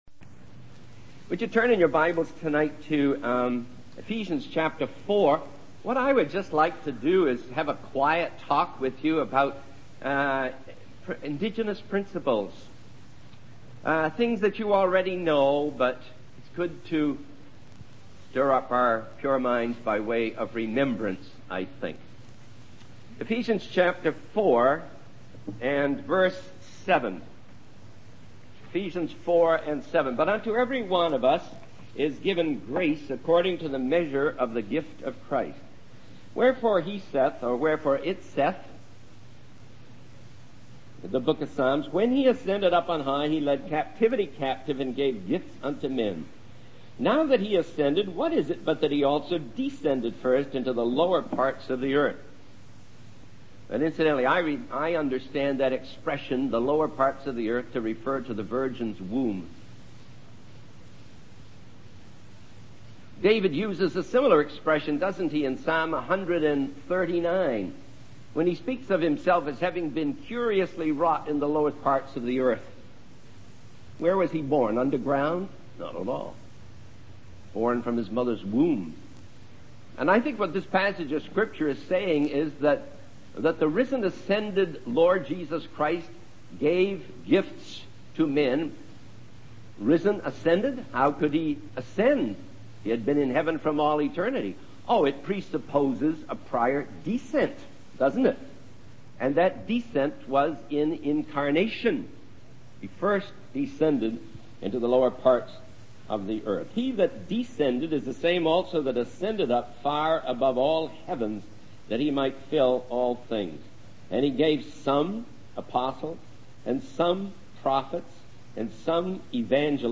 In this sermon, the speaker focuses on indigenous principles and the importance of empowering local believers in spreading the gospel. He references Ephesians 4:7, which states that each believer is given grace according to the measure of the gift of Christ.